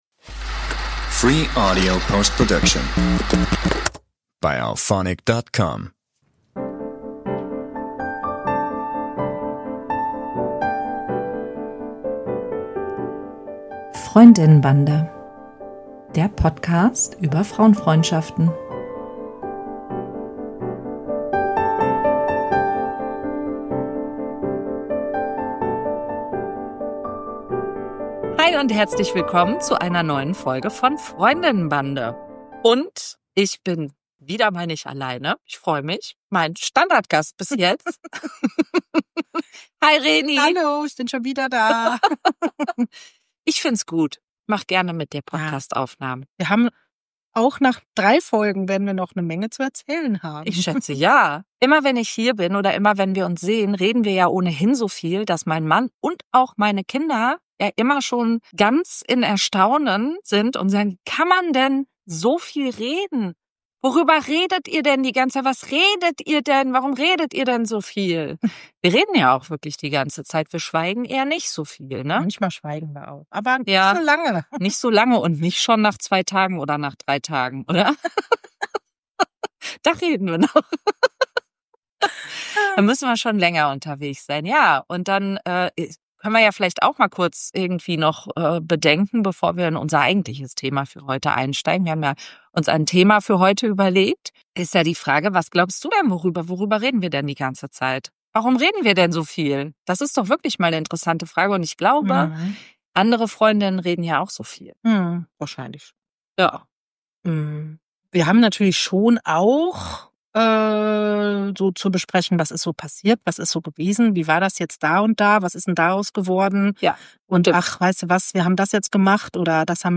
Wir versuchen es im Gespräch in unsere Reihenfolge zu bringen und von dem zu erzählen, was uns im Rückblick wichtig erscheint.